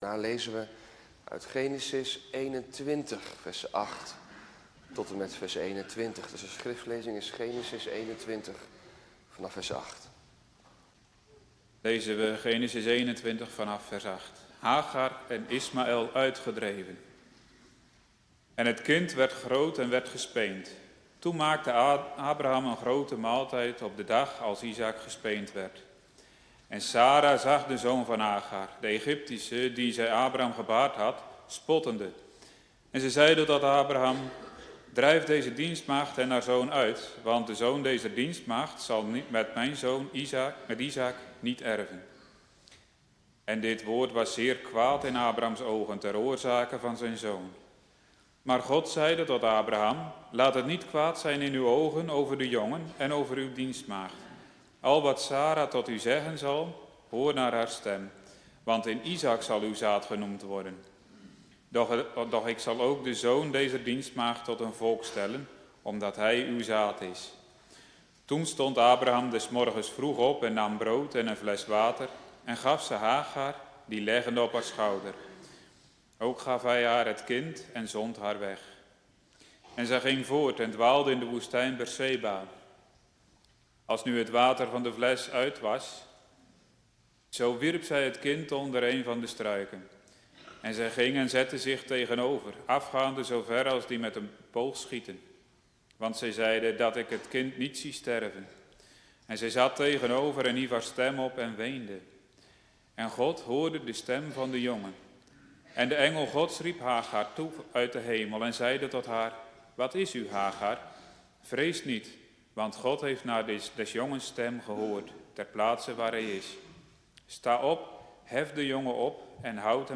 Twee woestijngangers prekenserie ‘tweetallen in het OT’